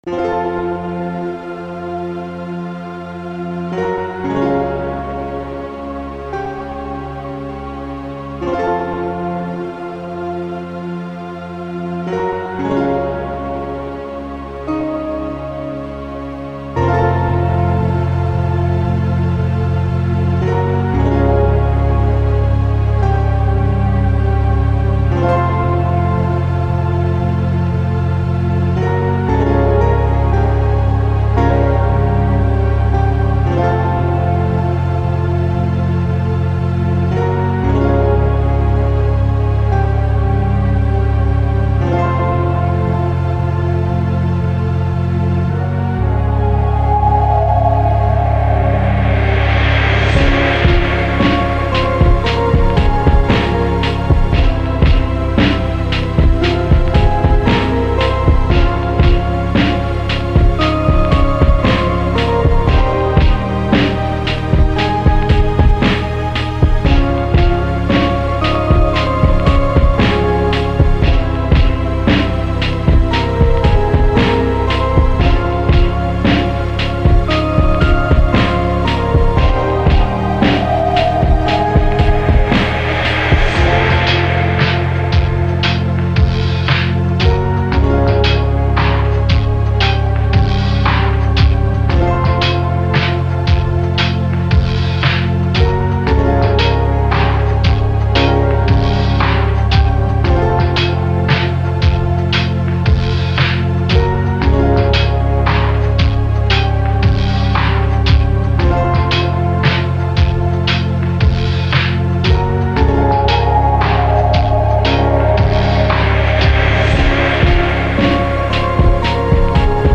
relaxing melody